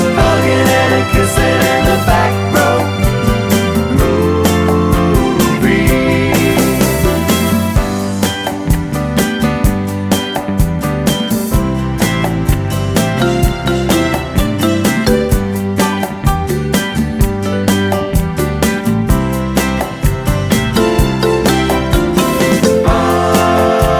no Backing Vocals Soul / Motown 3:34 Buy £1.50